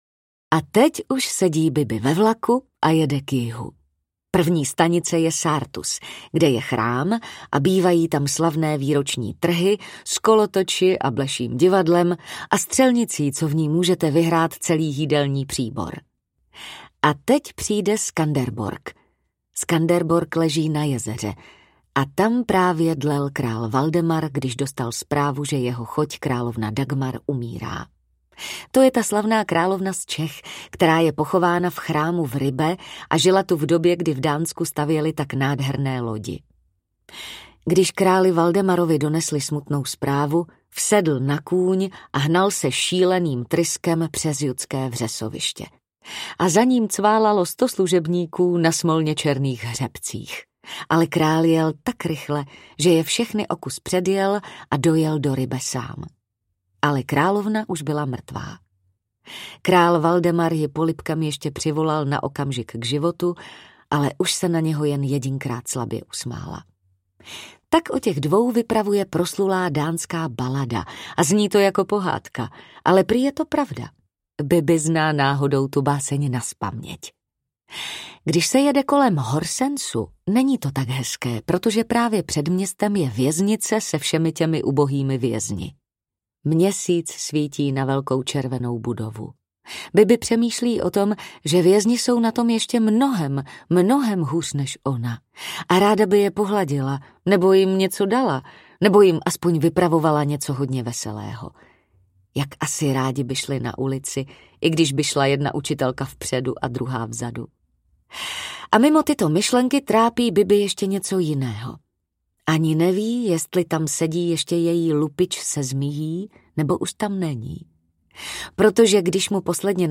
Ukázka z knihy
Vyrobilo studio Soundguru.
bibi-audiokniha